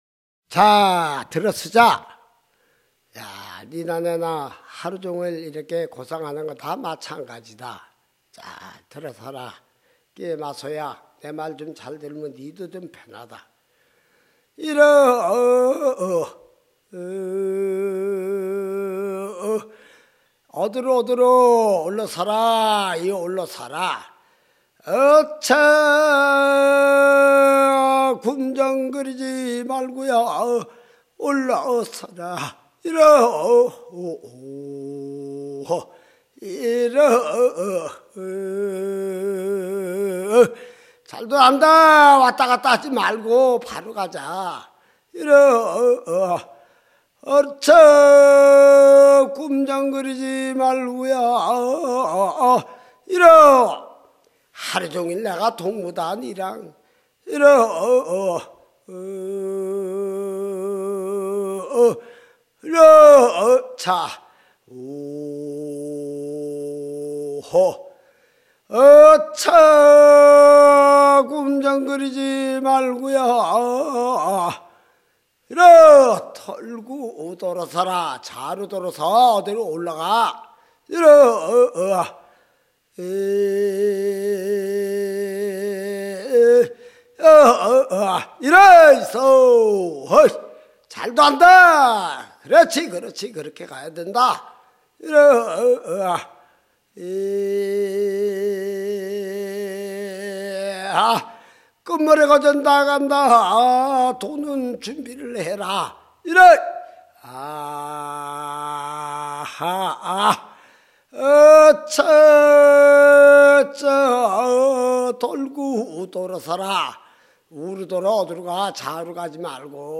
牛追い歌と農作業歌 - 畑歌は田歌、代掻き歌とともに江原道に特徴的に分布する民謡である。